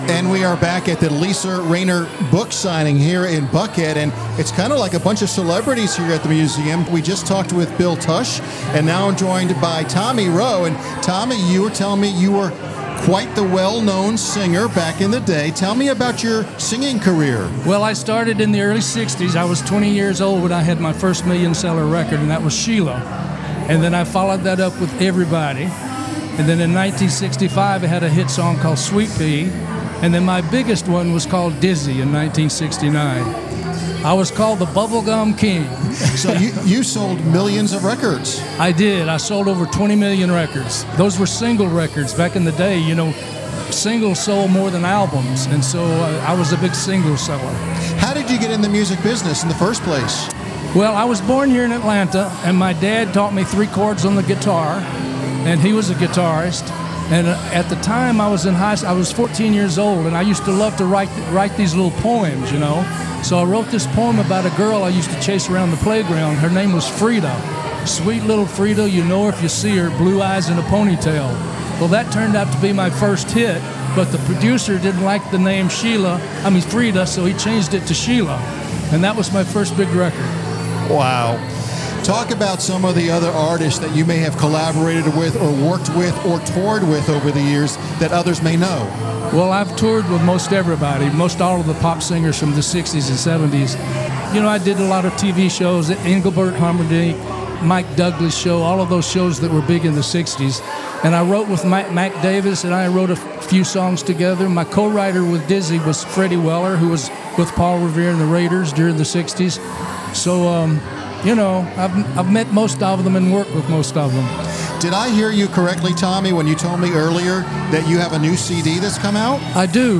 Business RadioX was on site to interview the author and distinguished guests attending the event, courtesy of Riptide, LLC.